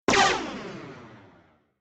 blaster.mp3